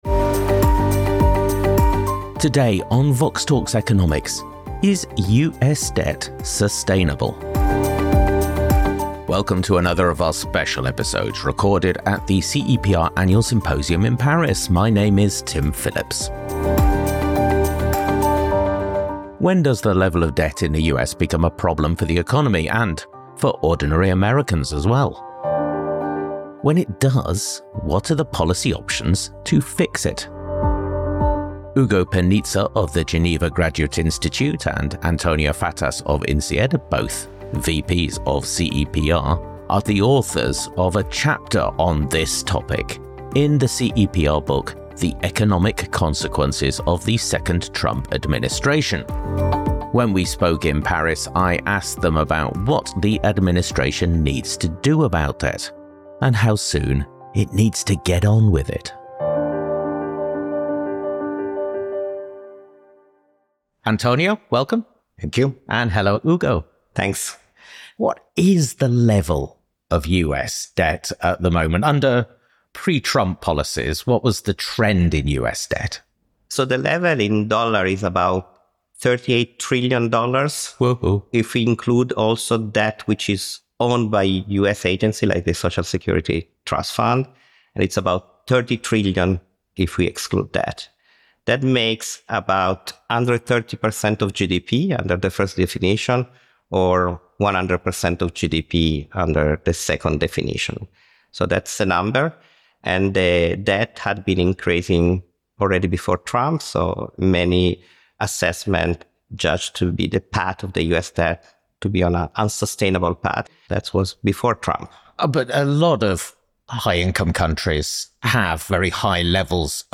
Another special episode recorded at the CEPR annual symposium in Paris.